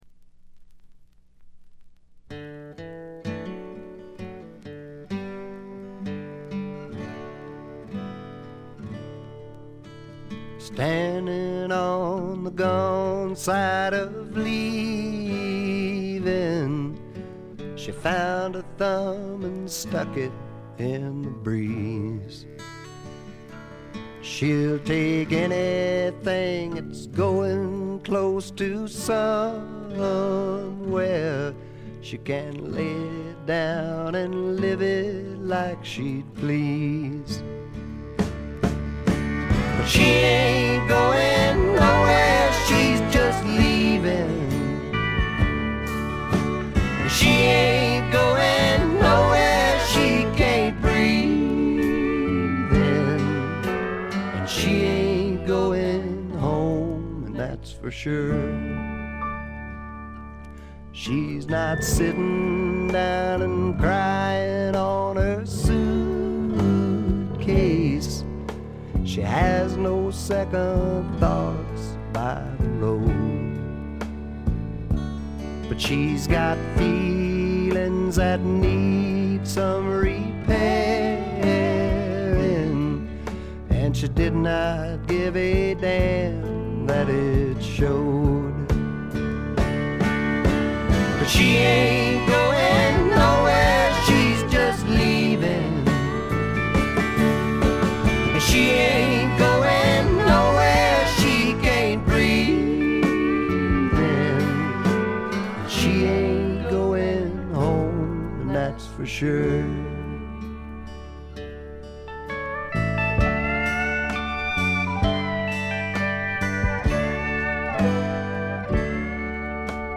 軽微なチリプチ少々。
朴訥な歌い方なのに声に物凄い深さがある感じ。
試聴曲は現品からの取り込み音源です。